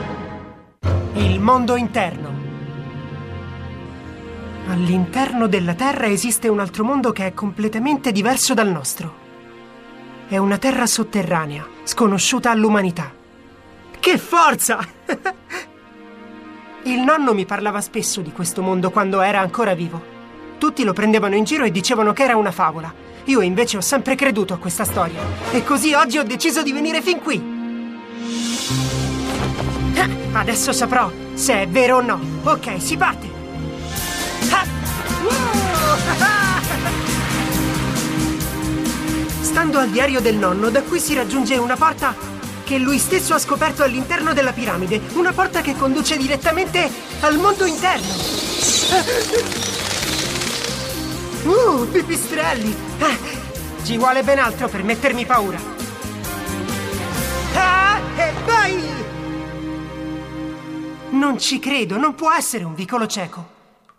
nel cartone animato "Spider Riders", in cui doppia Hunter Steel.